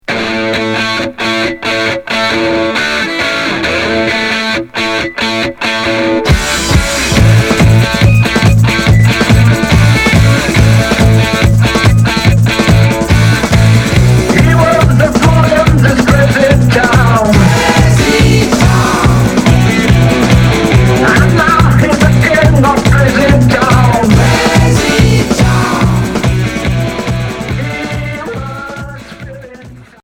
Rock glam